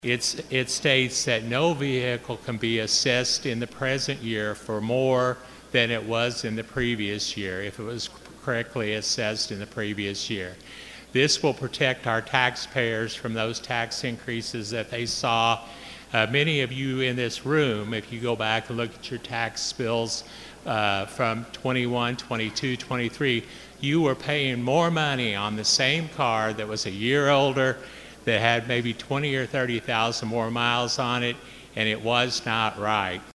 He told his colleagues in the House Chamber,